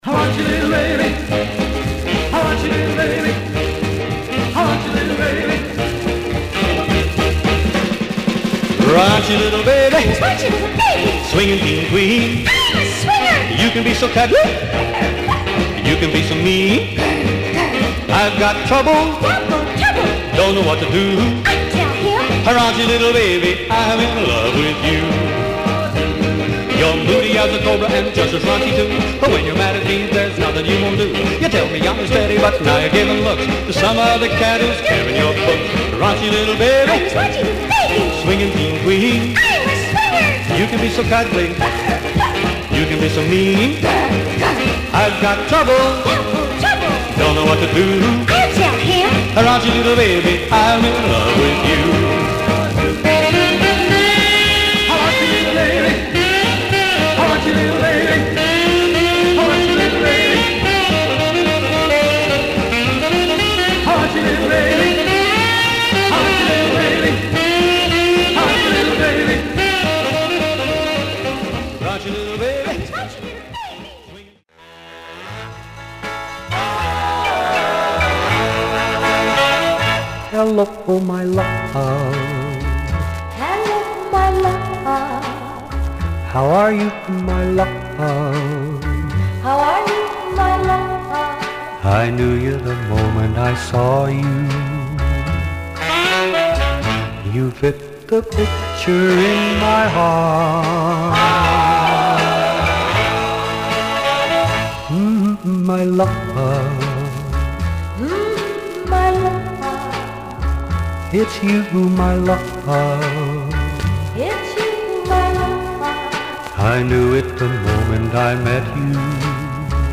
Mono
Rockabilly